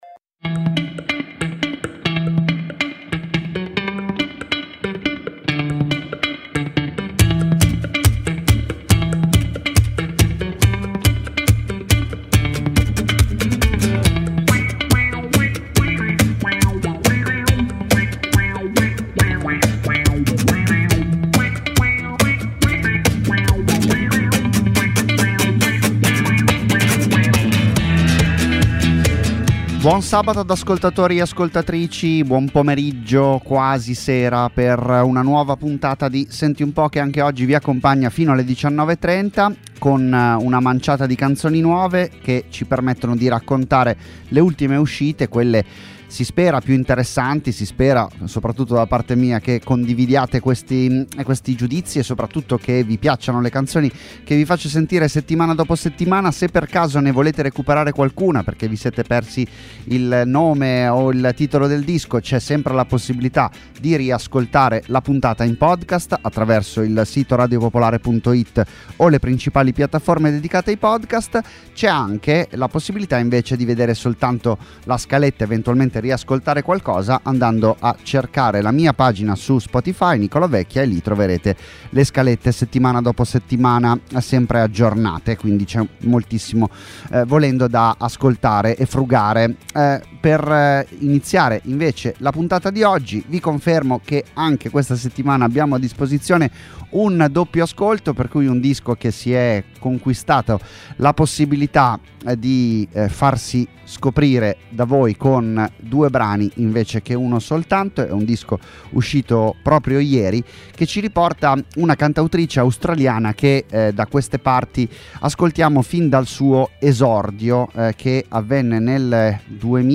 Ospiti, interviste, minilive, ma anche tanta tanta musica nuova. 50 minuti (circa…) con cui orientarsi tra le ultime uscite italiane e internazionali.